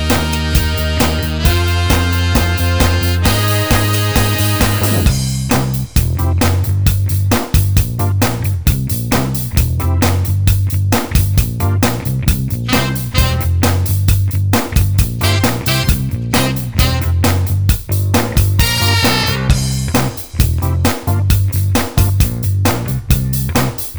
No Backing Vocals or Guitars Duets 4:16 Buy £1.50